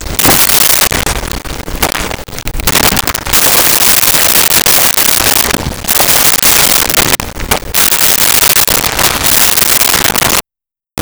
Monster Eat Chew
Monster Eat Chew.wav